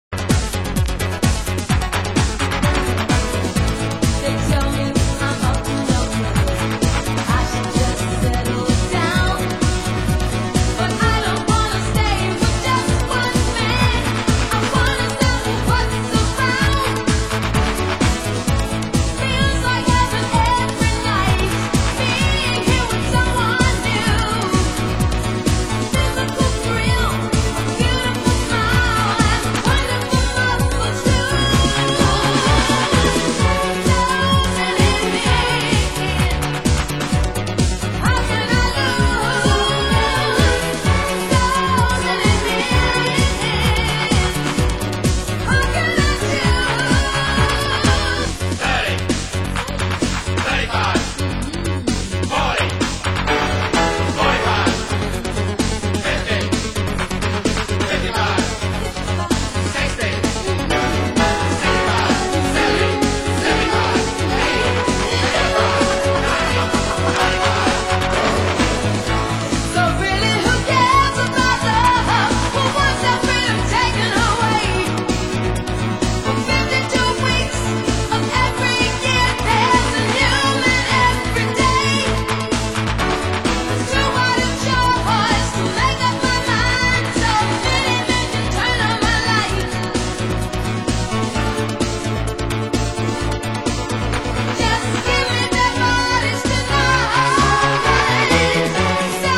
Format: Vinyl 12 Inch
Genre: Disco